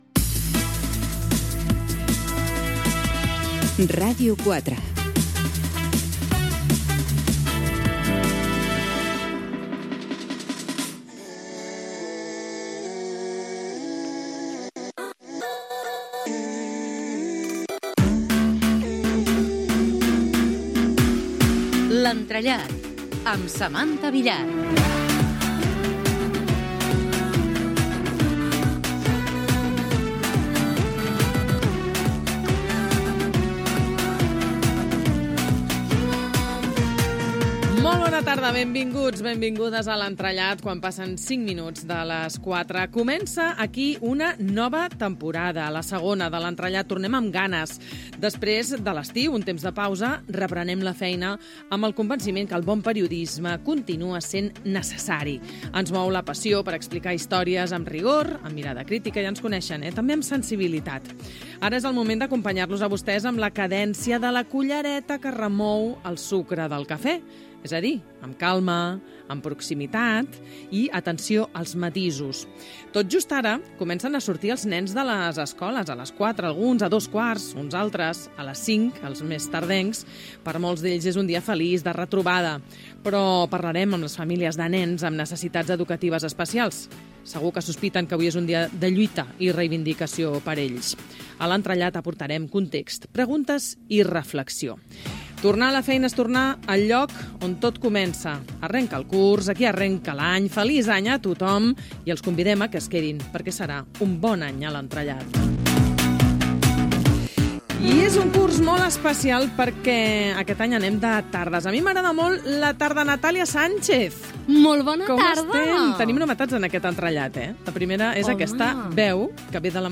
Indicatiu de la ràdio,avís del canvi d'horari. Diàleg de les presentadores i les col·laboradores expliquen de què parlaran al programa
Entreteniment